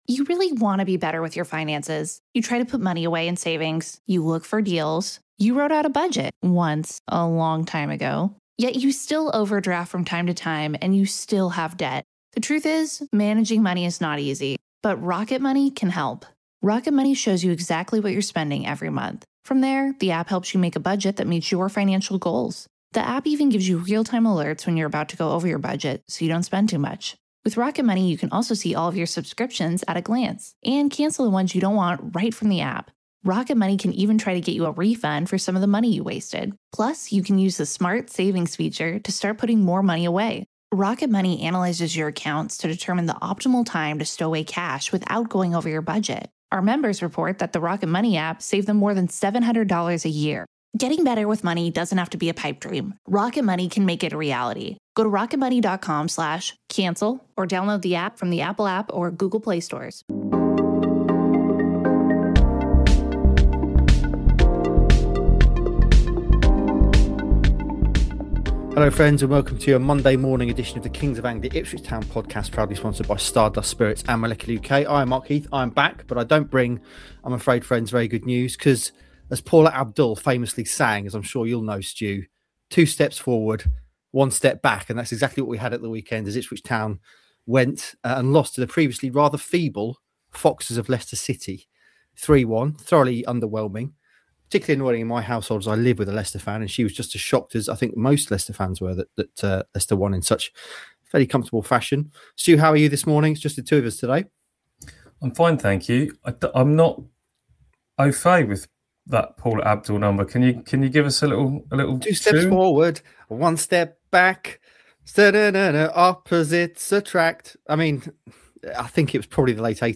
rare two man pod